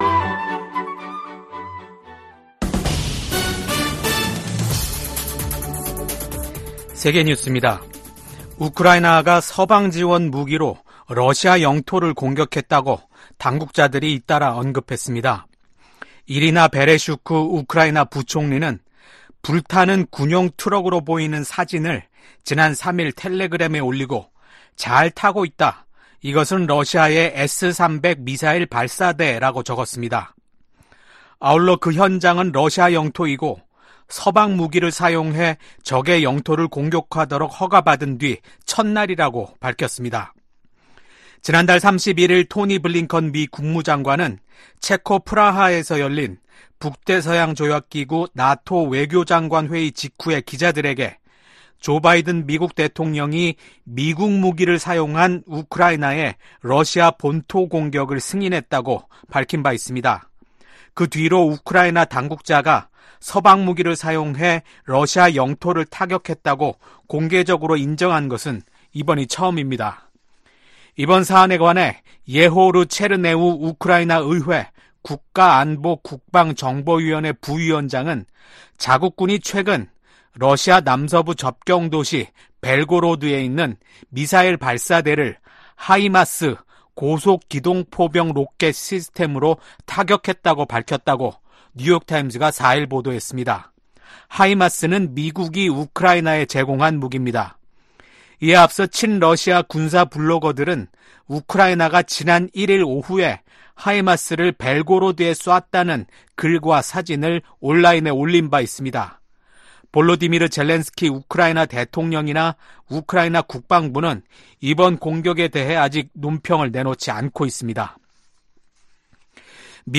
VOA 한국어 아침 뉴스 프로그램 '워싱턴 뉴스 광장' 2024년 6월 6일 방송입니다. 미국, 한국, 일본이 국제원자력기구 IAEA 정기 이사회에서 북한과 러시아의 군사 협력 확대를 비판하며 즉각 중단할 것을 한목소리로 촉구했습니다. 백악관이 북한 정권의 대남 오물풍선 살포 등 도발과 관련해 큰 우려를 가지고 주시하고 있다며 평양이 불필요한 행동을 계속하고 있다고 비판했습니다.